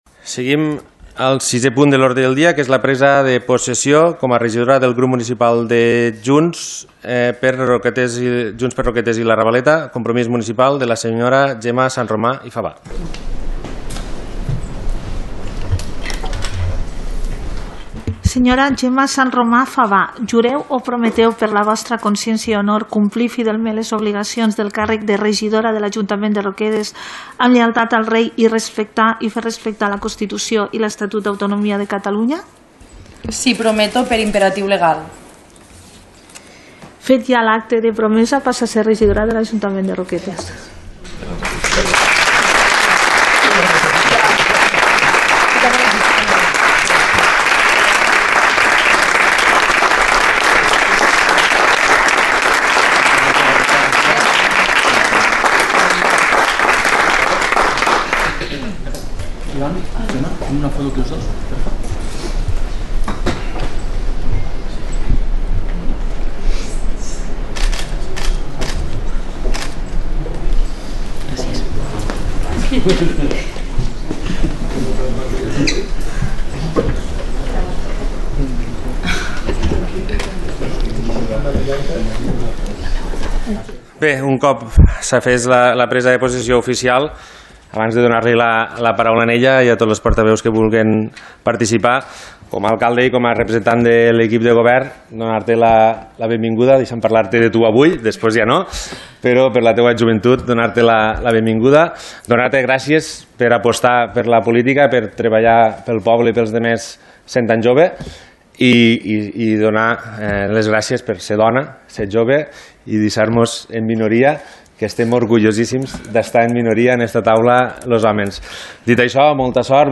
La nova edil del grup municipal de Junts per Roquetes i la Ravaleta ha pres possessió davant els membres de la corporació municipal, en l’últim punt de l’ordre del dia del ple ordinari corresponent al mes de gener.